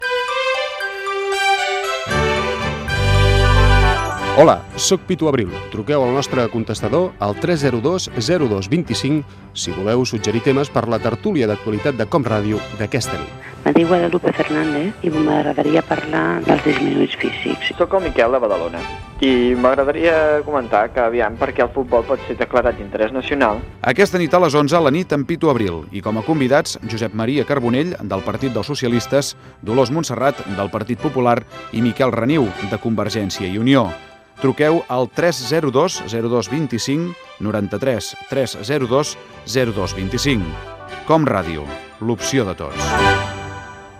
Promoció de la tertúlia "La nit"